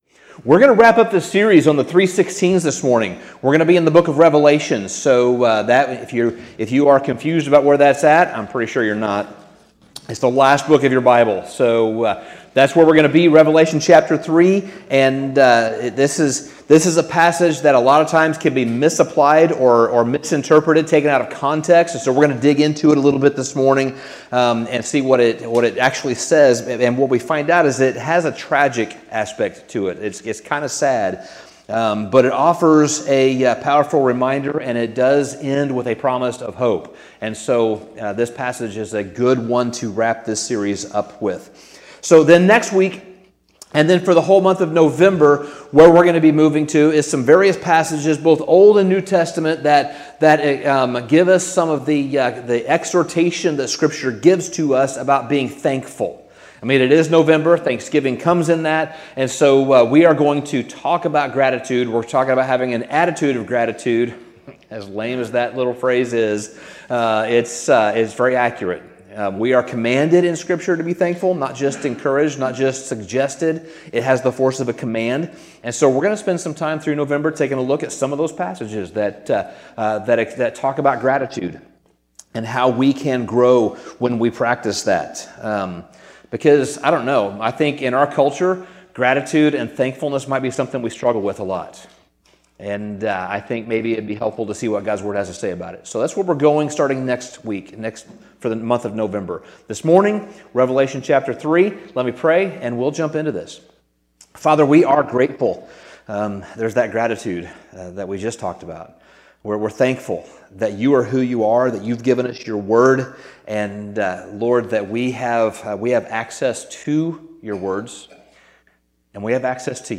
Sermon Summary In our final installment of this series, we turn our attention to Revelation 3, and the letter from Jesus to the church at Laodicea.